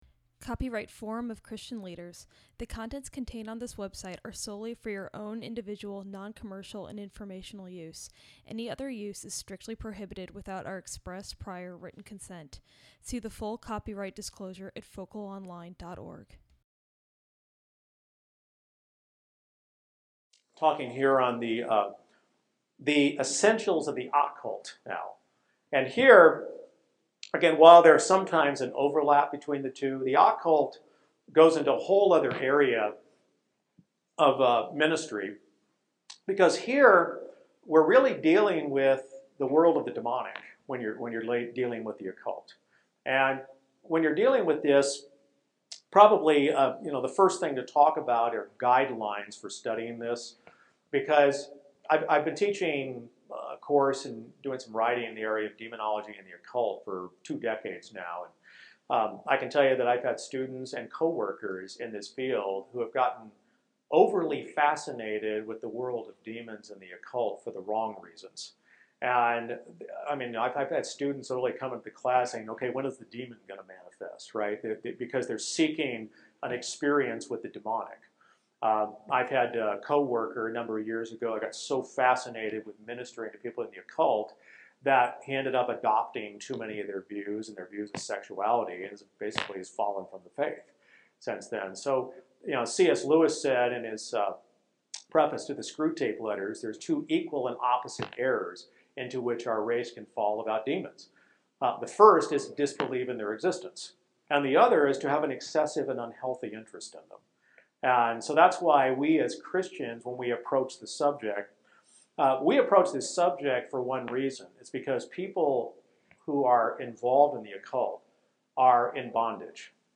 This lecture examines these and other occult-related issues.
Event: ELF Pre-Forum Seminar